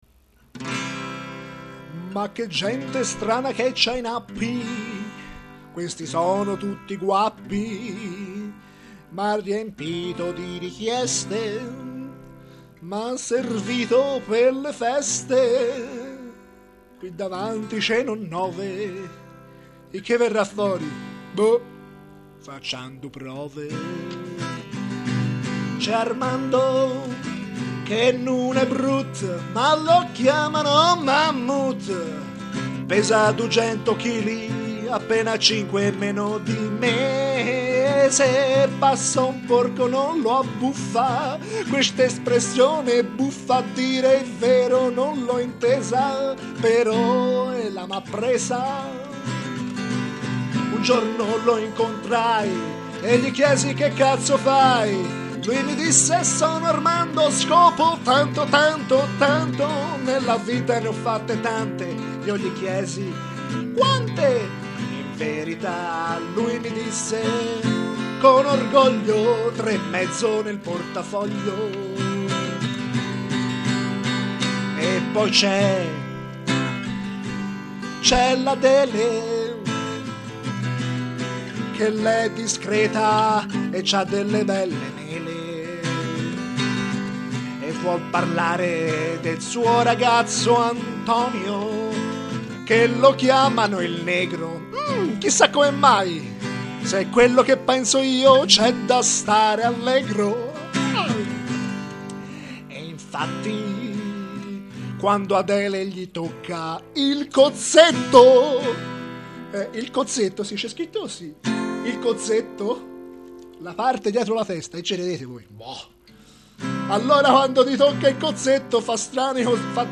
Cantata con passione e senza raziocinio.